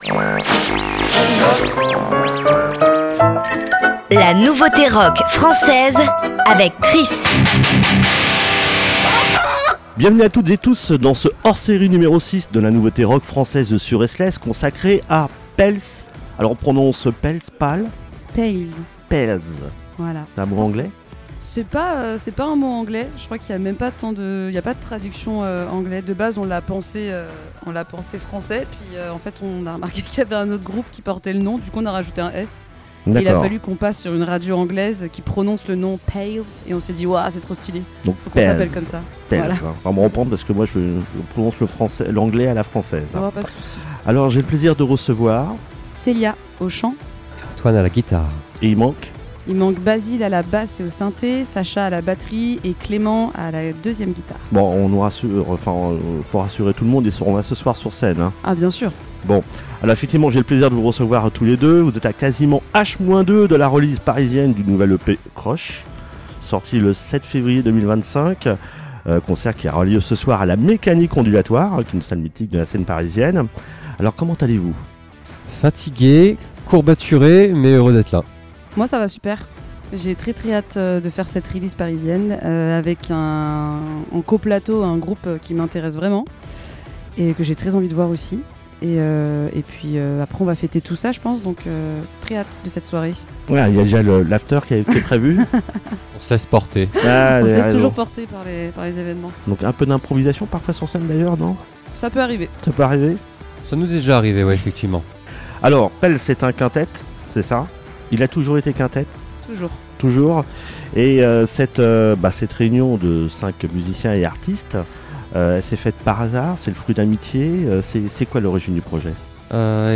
Etes-vous prêtes et prêts pour un CRUSH avec PALES ? partons à la rencontre au cours d’une interview réalisée par RSTLSS consacrée à ce quintet talentueux qui nous livre ses impressions et ses mots sur son dernier opus CRUSH en...